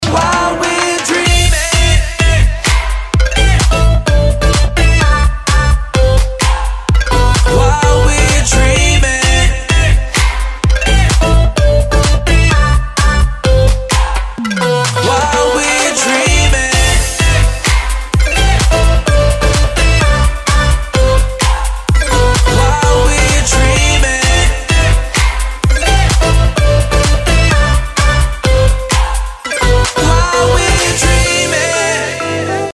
• Качество: 320, Stereo
мужской голос
dance
Electronic
EDM
романтичные
Заводной музончик!